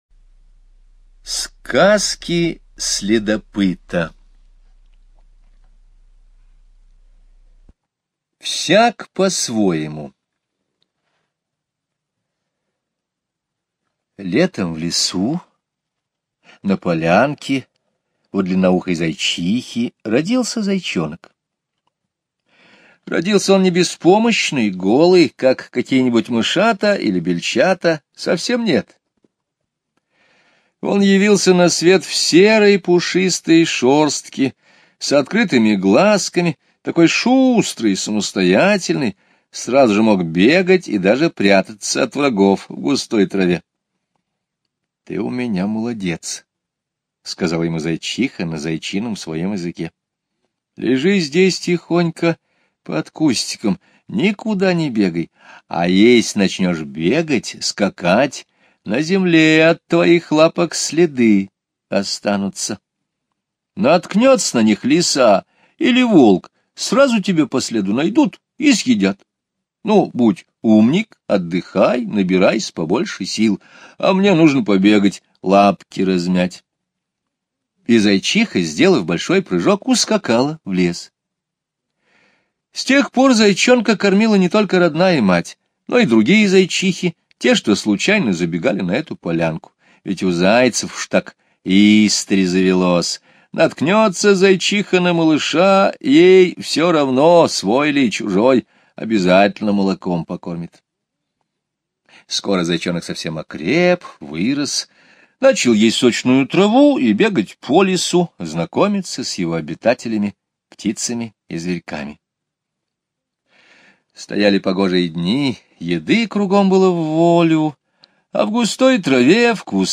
Слушайте аудио рассказ "Всяк по-своему" Скребицкого Г. онлайн на сайте Мишкины книжки. Зайчонок, родившийся летом, к осени подрос и заметил, что многие лесные жители начали делать запасы еды на зиму, а многие птицы улетели на юг. skip_previous play_arrow pause skip_next ...